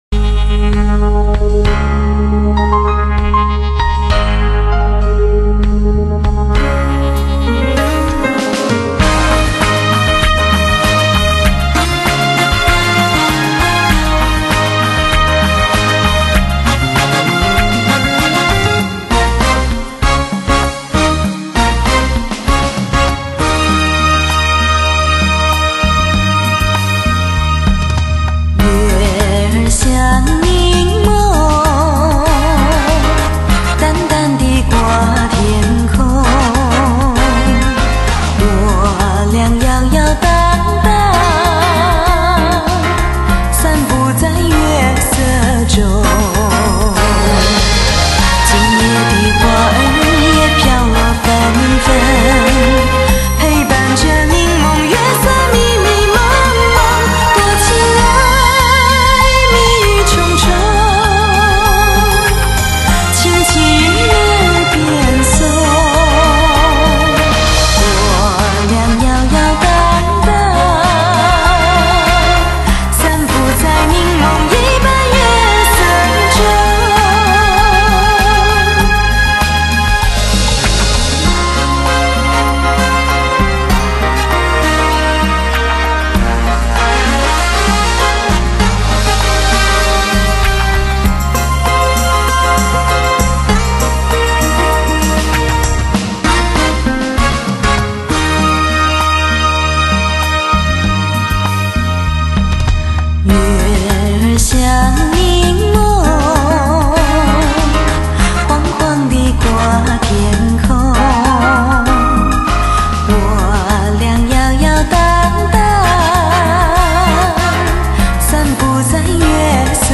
發燒極品，百聽不厭;全頻段六聲道製作如同置身音樂聽之中。傳奇真空管處理，頂級音效環繞體验！